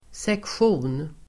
Uttal: [seksj'o:n]